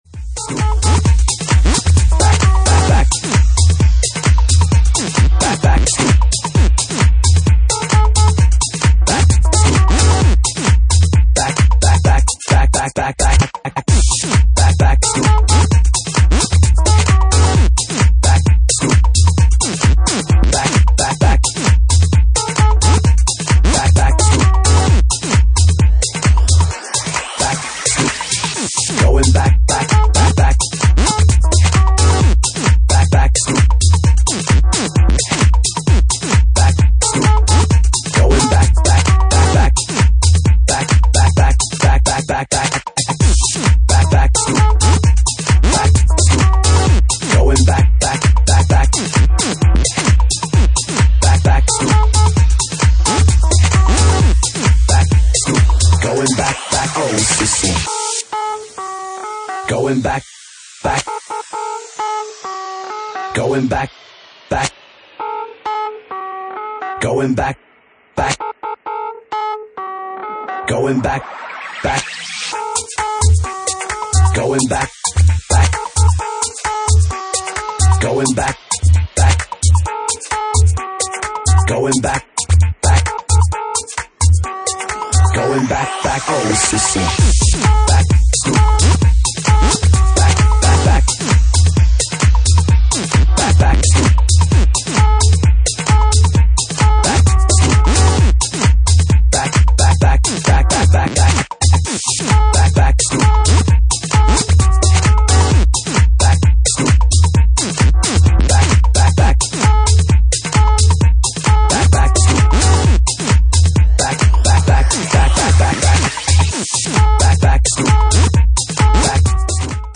Genre:Bassline House
Bassline House at 66 bpm